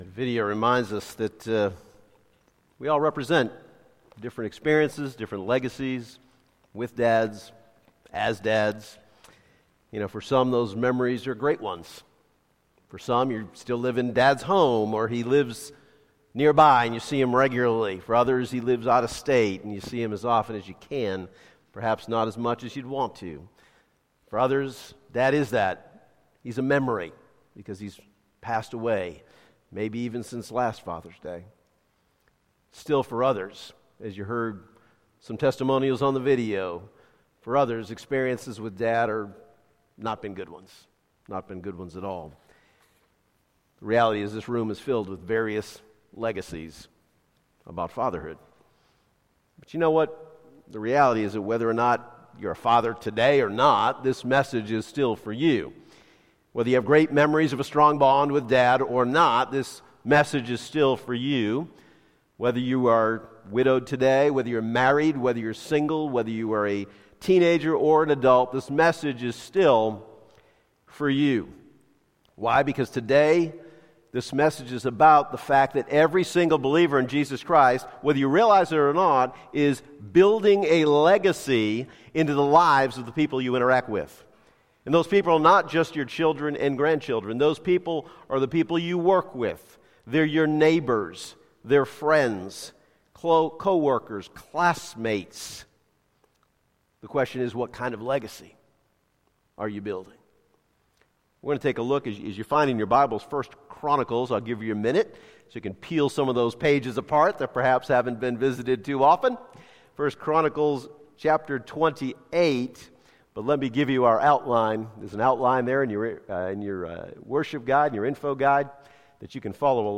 Sermons - Calvary Baptist Bel Air